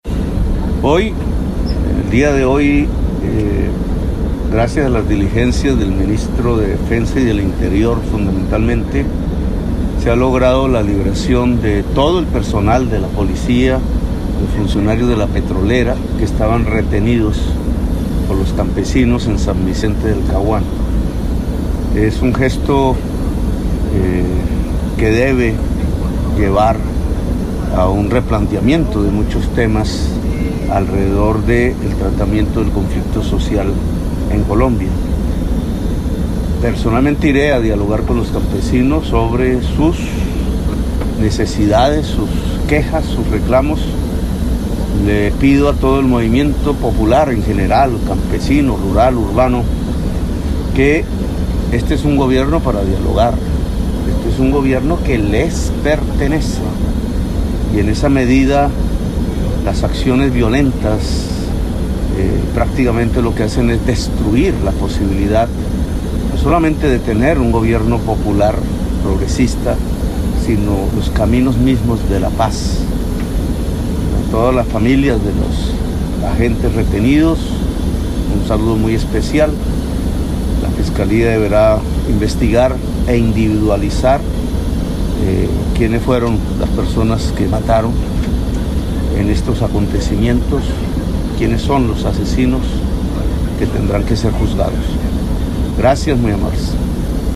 voz_petro.mp3